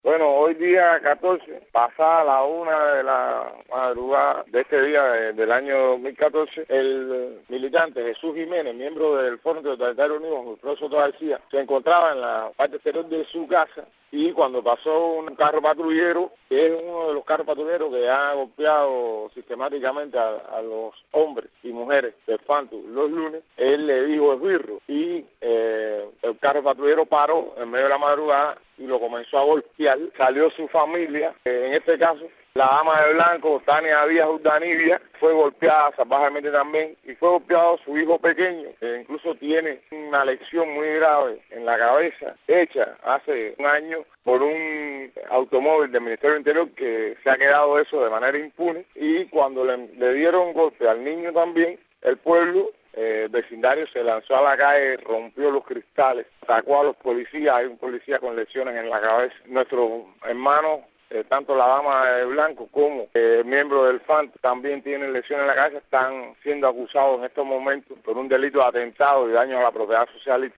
Una familia opositora fue golpeada y detenida en la madrugada de este miércoles en la ciudad de Santa Clara. El activista, Guillermo Fariñas amplía la información.